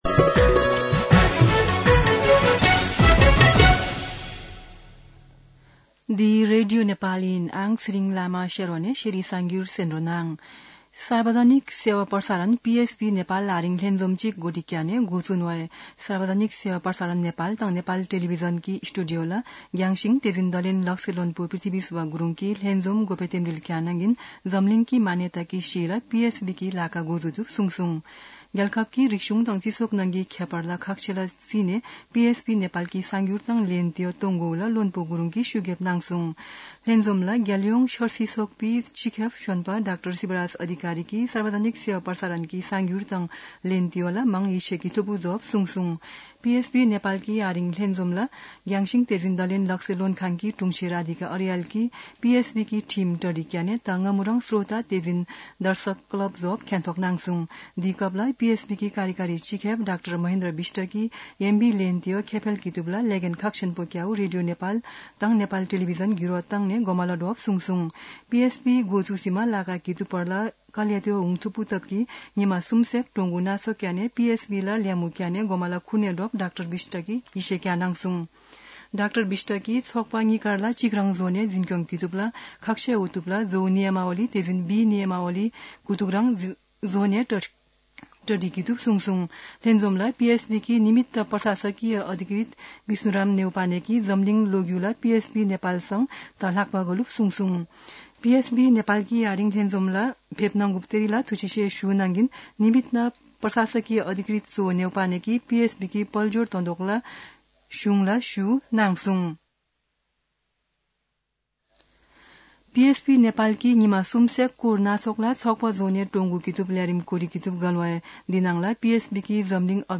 शेर्पा भाषाको समाचार : २ माघ , २०८१
Sherpa-News-4.mp3